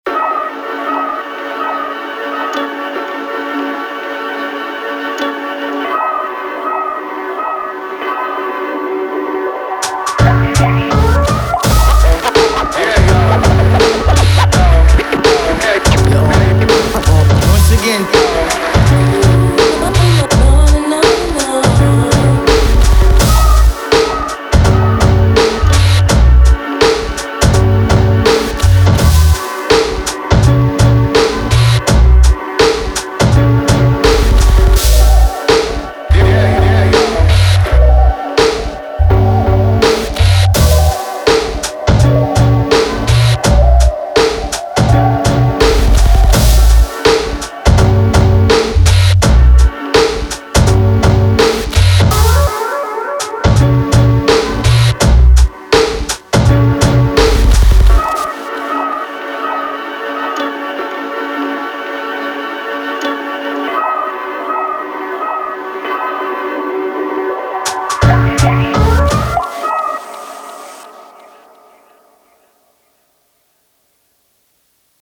Real cutting in there too?
well a sc1000 rutine so a modern aproach :wink: